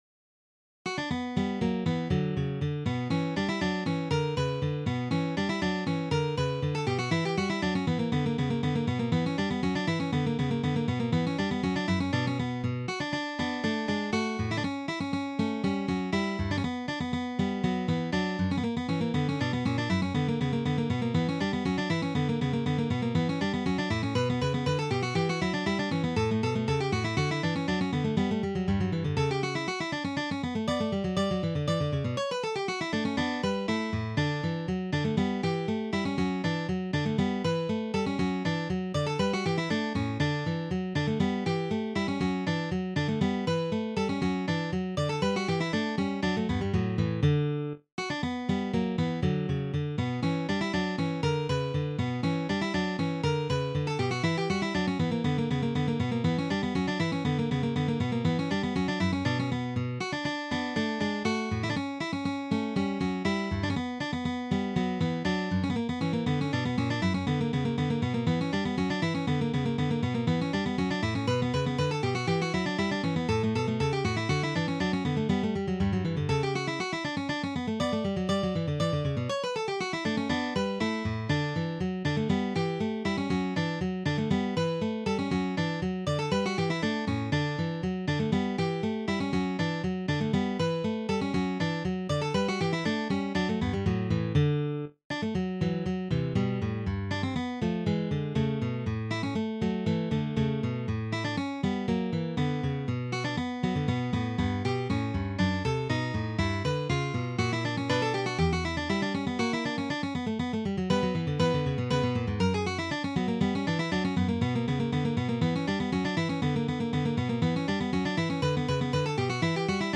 written for two guitars